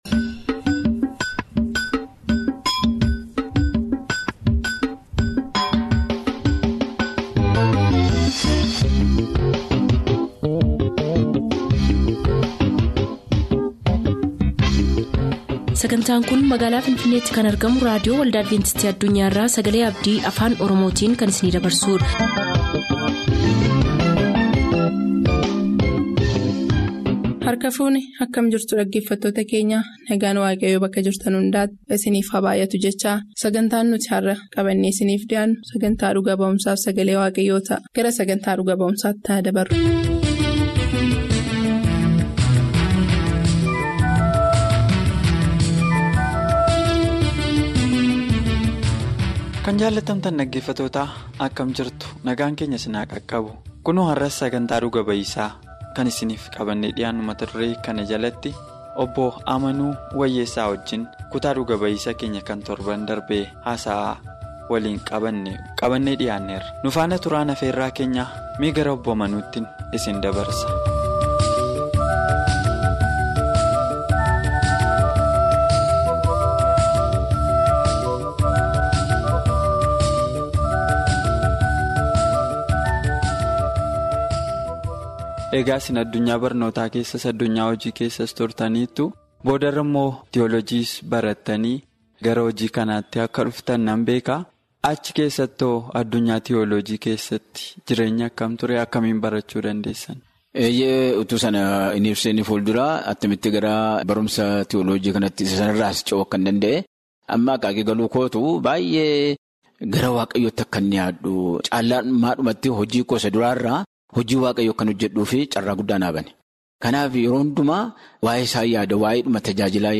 MUUXANNOO JIREENYYAA FI LALLABA. WITTNESING AND TODAY’S SERMON